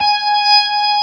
55O-ORG19-G#.wav